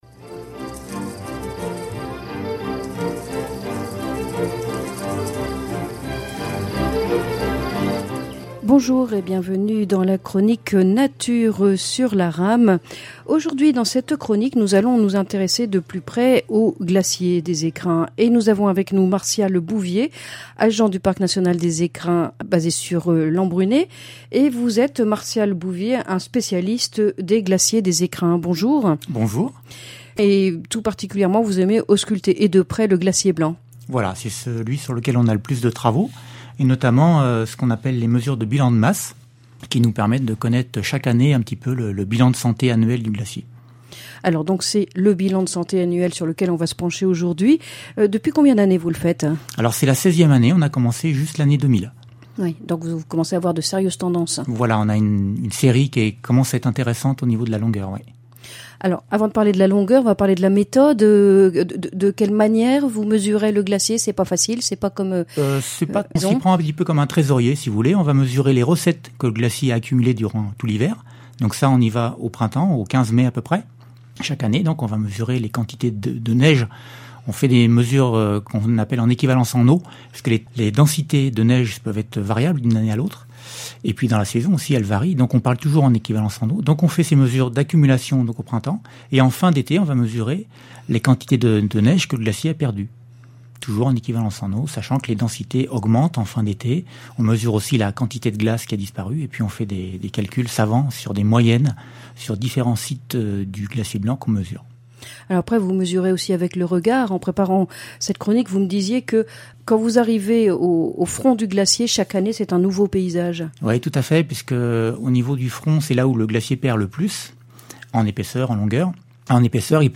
chronique nature RAM